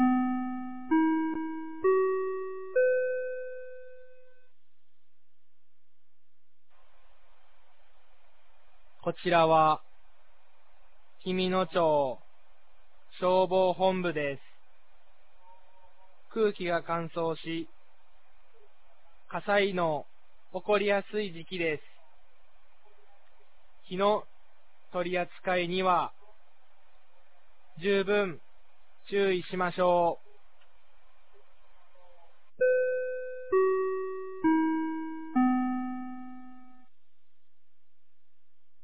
2023年11月04日 16時00分に、紀美野町より全地区へ放送がありました。